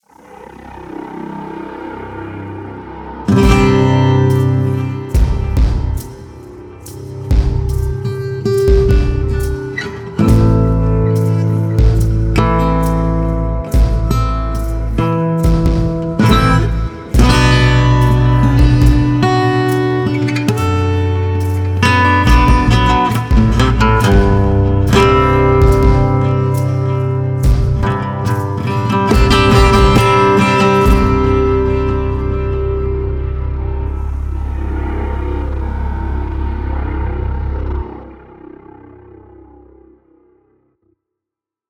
Cartoon Voice Over
YouTube • 7th November 2023 Juniper | Prediabetes Education Video Editing, Ideation, Direction, Social Cutdowns 9th February 2024 Cartoon Voice Over As a challenge to myself I created a cartoon voiceover. I also did the sound design and mixing.